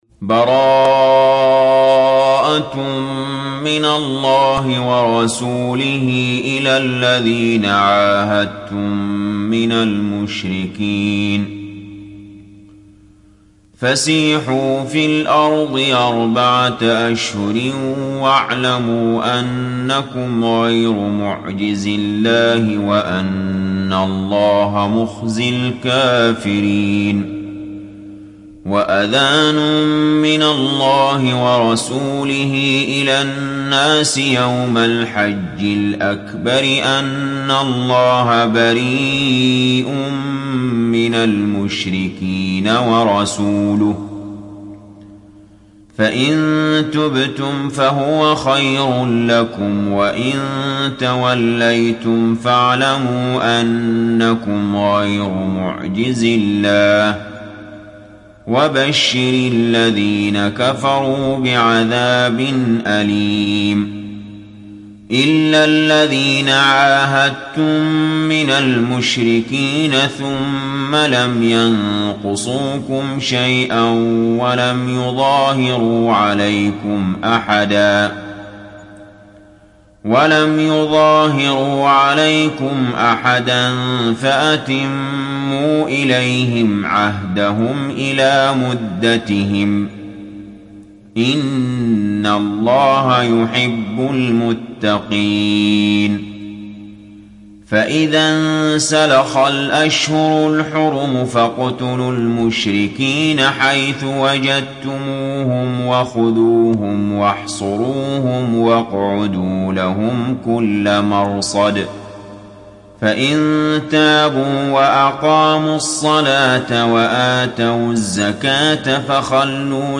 Sourate At Tawbah Télécharger mp3 Ali Jaber Riwayat Hafs an Assim, Téléchargez le Coran et écoutez les liens directs complets mp3
Moratal